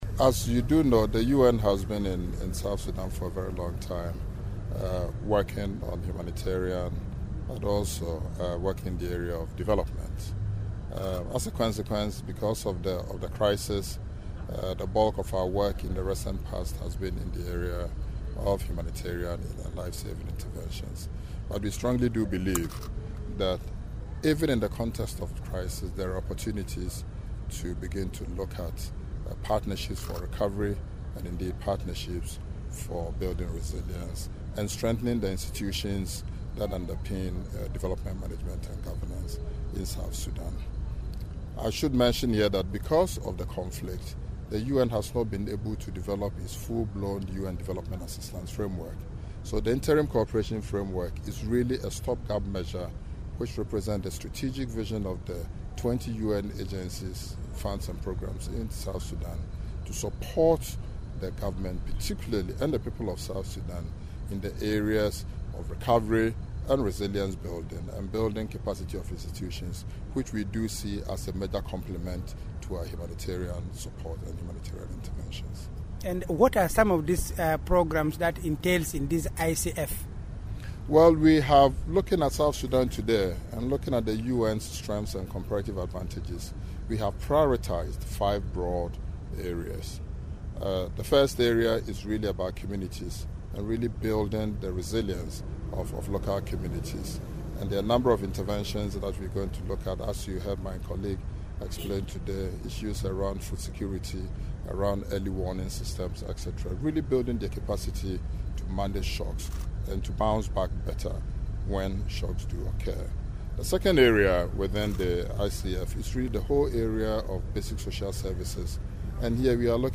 UN Resident and Humanitarian Coordinator, Eugene Owusu explains the overall objective of the ICF.